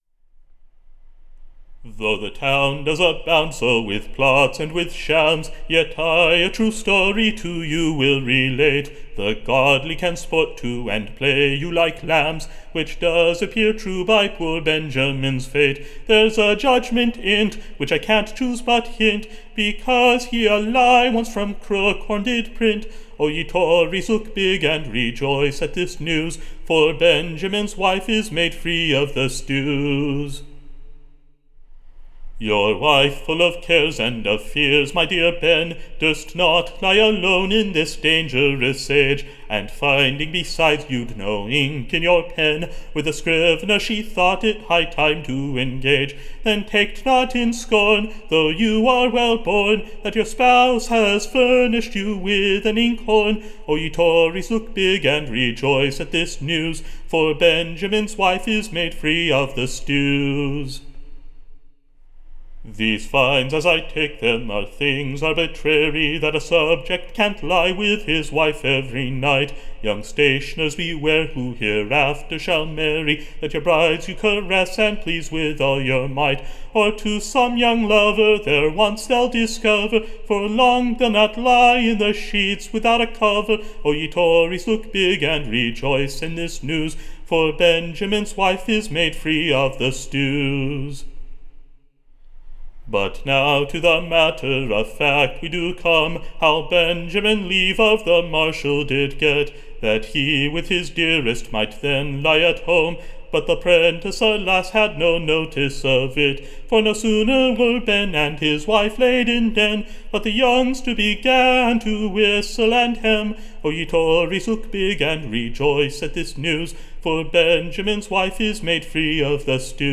Recording Information Ballad Title The Protestant CUCKOLD: / A New BALLAD.
Tune Imprint To the Tune of Packingtons Pound; Or, Timothy Dash the Scriveners Apprentice.